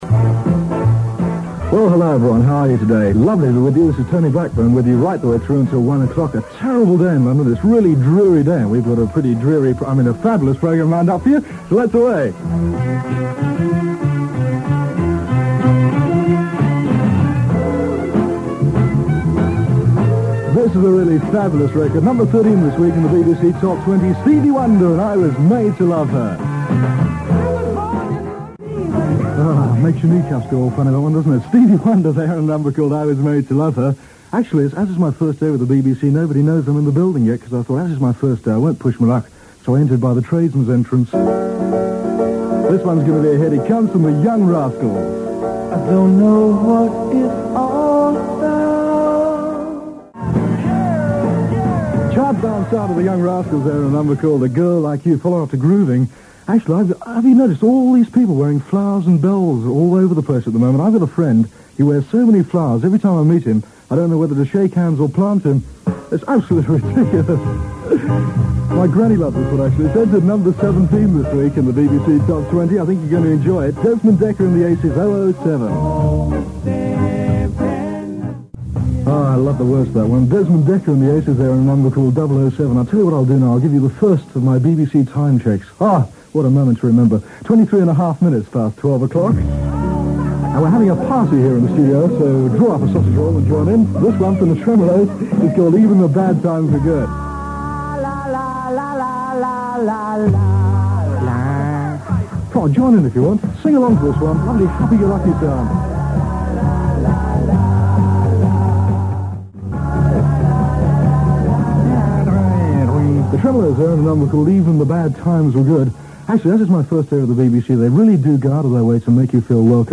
After some educative years on board the pirates, Tony Blackburn returned home just before the pirates faded to join the BBC on the Light Programme, just weeks before it split into Radio 1 and 2. Enjoy here his debut for Auntie in August 1967, with his familiar sig tune, gags and trademark smile - and just a hint of 'new boy'.
Witness how old and new come together beautifully when Tony enters a very plummy BBC news bulletin, although the reader tries the very best he can to return to Tony's programme with suitable energy.